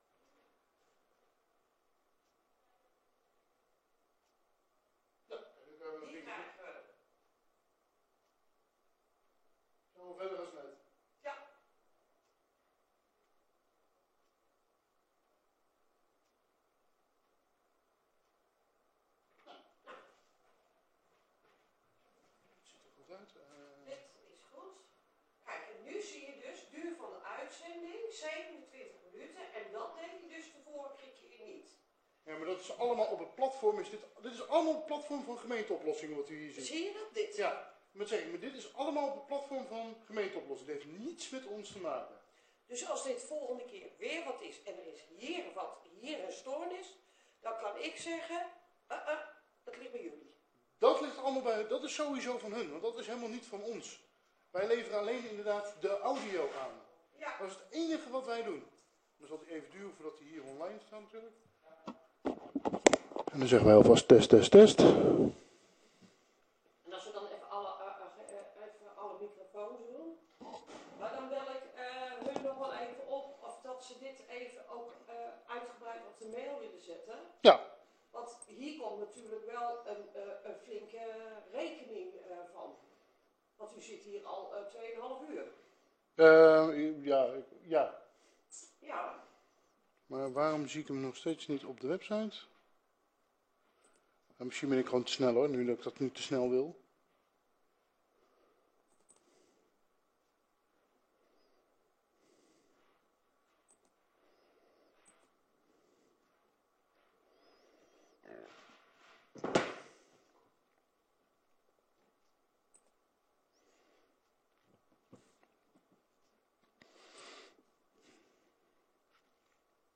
Locatie: Raadzaal